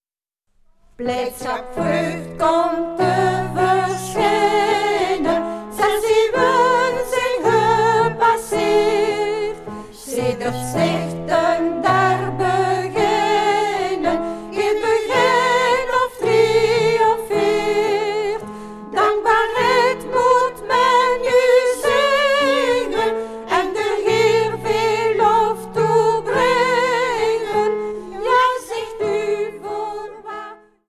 begijnen van het Groot Begijnhof van Sint-Amandsberg
Klavier en solozang:
Studio G.S.T. Gent.